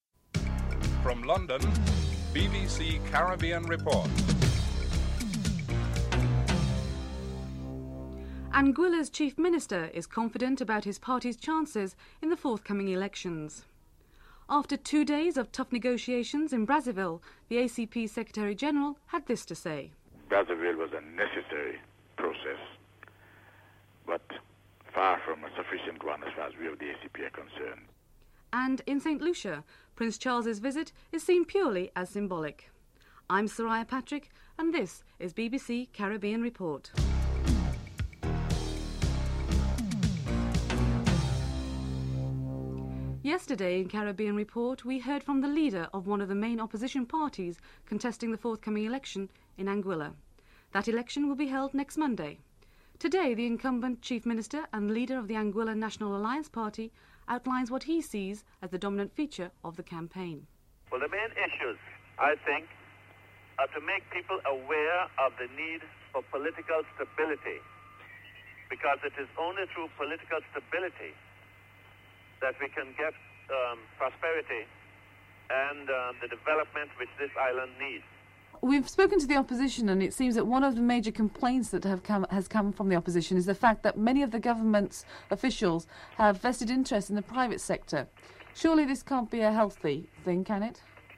1. Headlines (00:00-00:42)
3. Interview with ACP Secretary General, Edwin Carrington on his assessment of the Brazzaville Meeting (03:46-08:40)
4. Financial News (08:41-10:08)